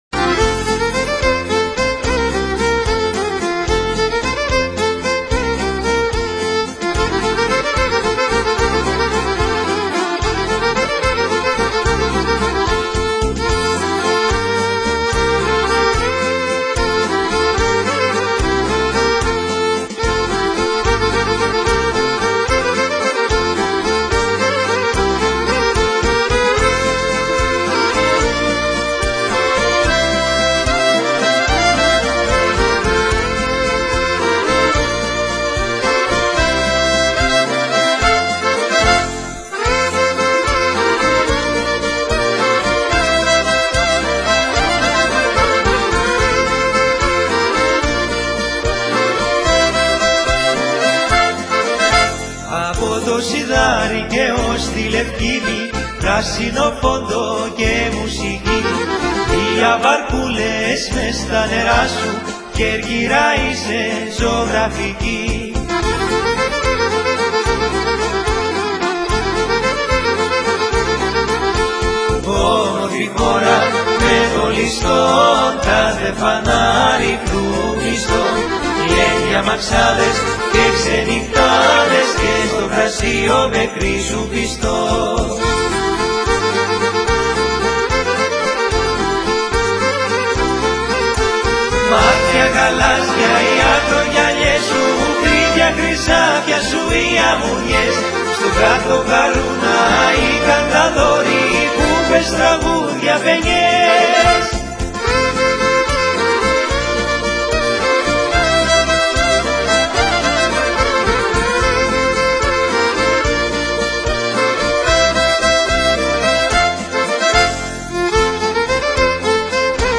Lasen Sie sich durch die schöne hindergrunmusik
korfusong.wma